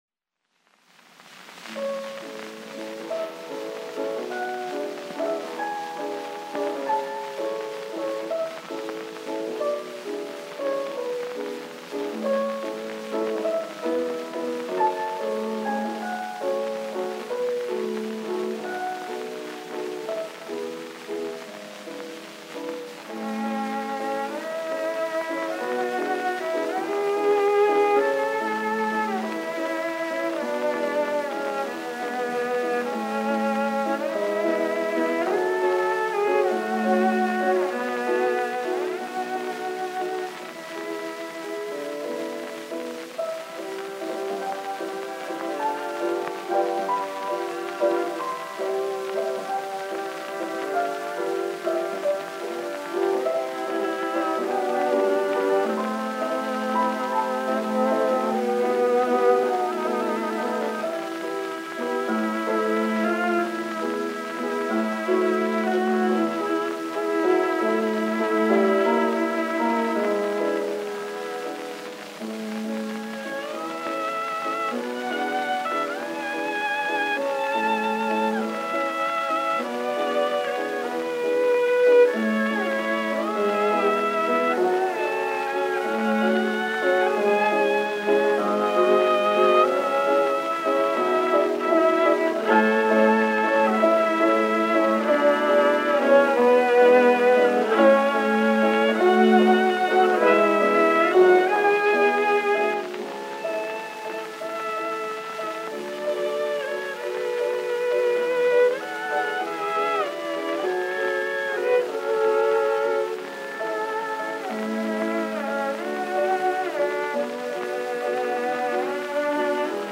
The Tollefsen Trio.
Piano, violin and cello.
Piano trios.